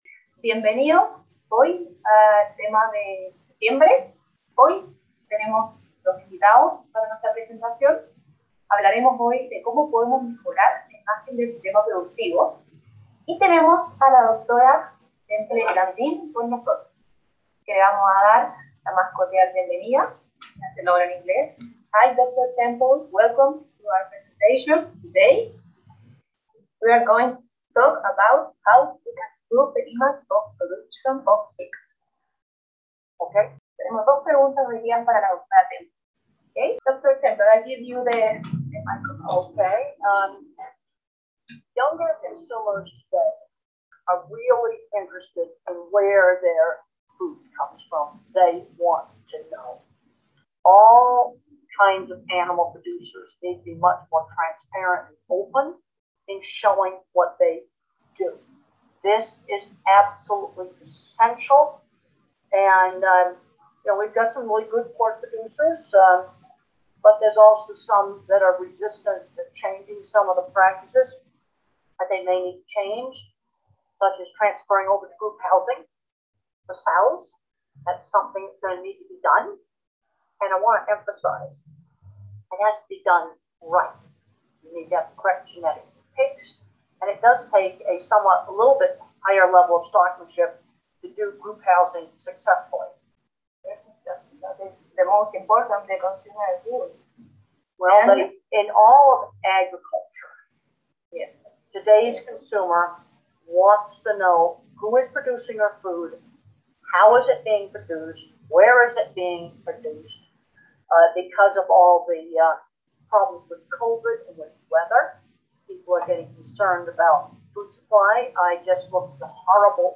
Entrevista a la Dra. Temple Grandin ¿Cómo mejorar los sistemas de producción porcina?
EntrevistaTempleGrandin.mp3